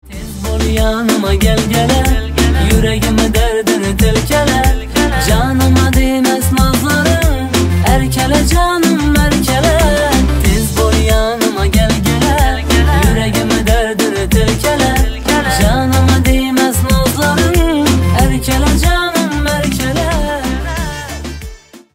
Узбекские